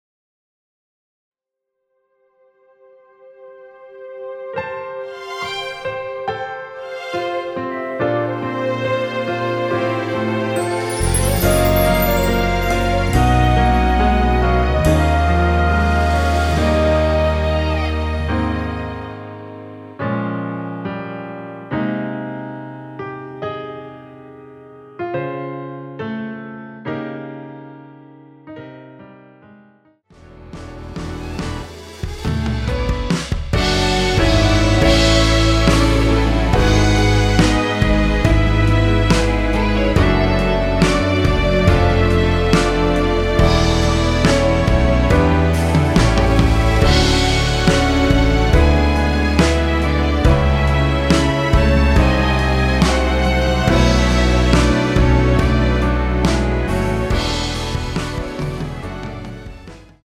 Ab
노래방에서 음정올림 내림 누른 숫자와 같습니다.
앞부분30초, 뒷부분30초씩 편집해서 올려 드리고 있습니다.
중간에 음이 끈어지고 다시 나오는 이유는